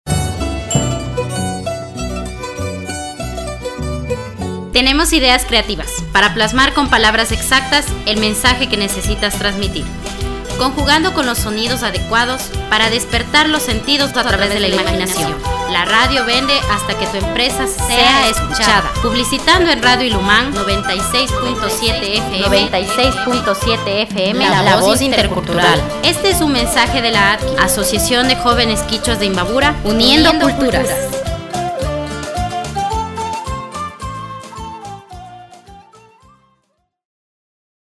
CUÑA RADIO ILUMAN.mp3